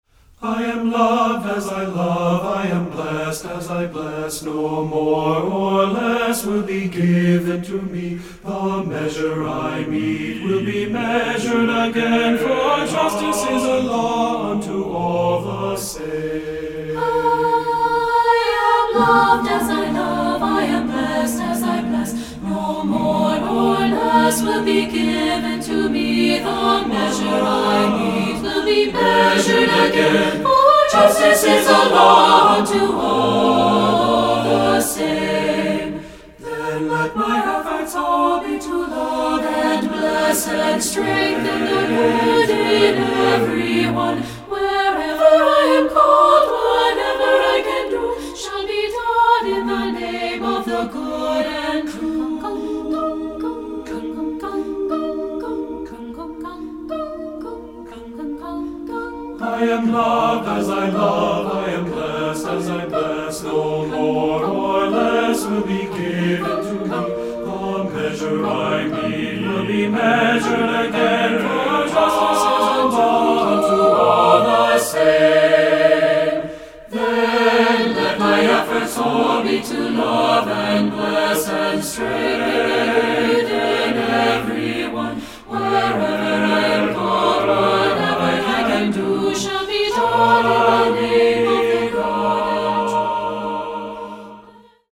Choeur Mixte (SATB) a Cappella